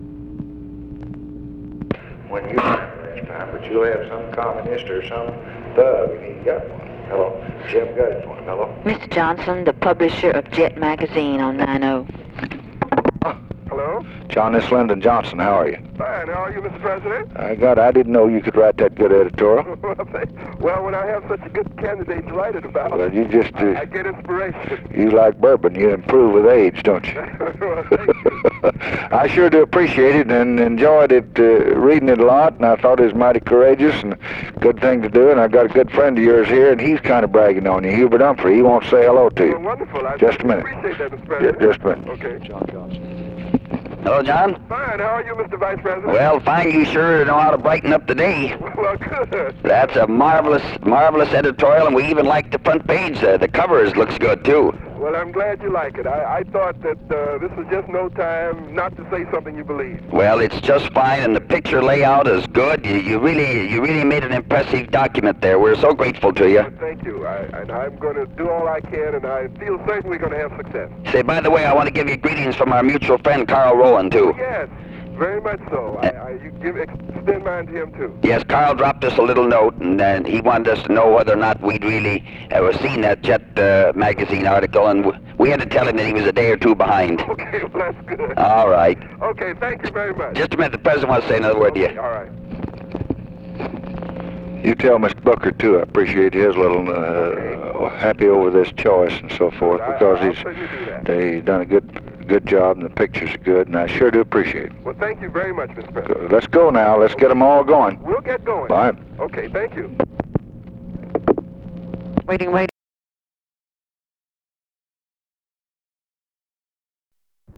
Conversation with JOHN JOHNSON and HUBERT HUMPHREY, September 4, 1964
Secret White House Tapes